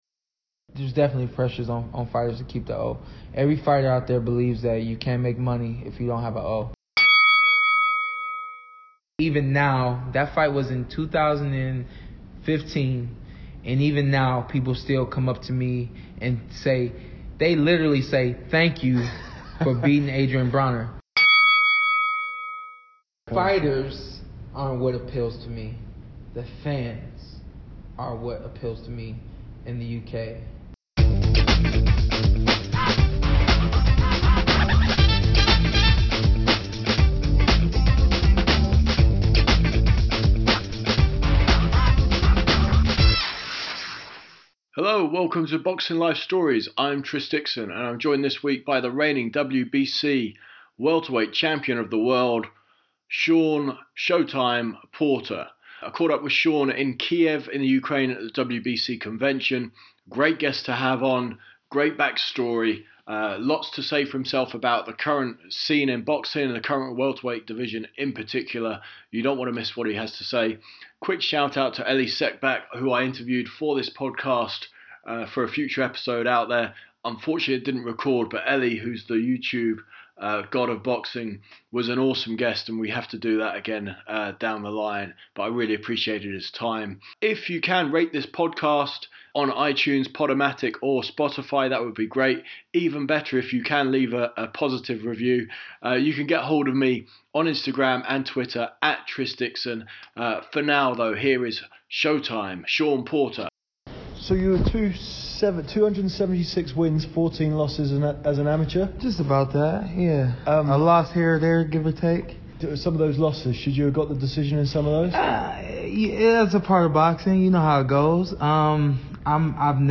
WBC welterweight champion Shawn Porter takes listeners through his life in boxing, from his Olympic heartbreak to shutting Adrien Broner's mouth and wearing championship gold. Here is Porter, one of the best interviews in boxing today, unplugged.